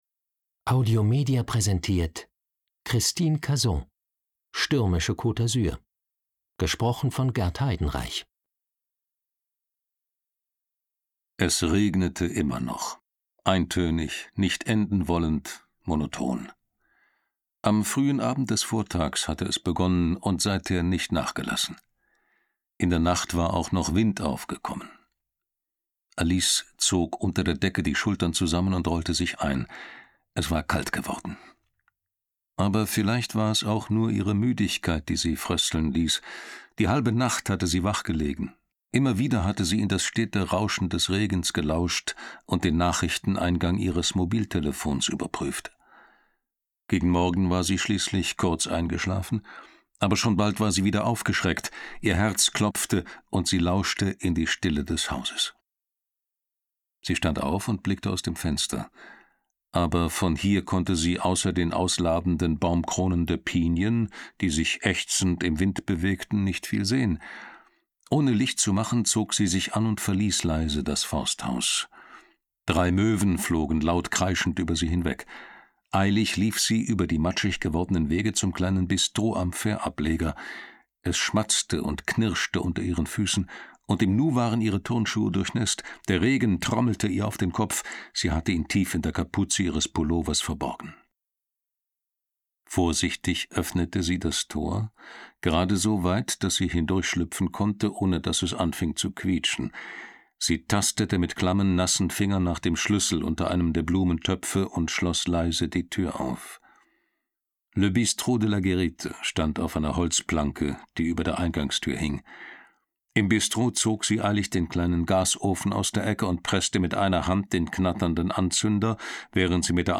Mitarbeit Sprecher: Gert Heidenreich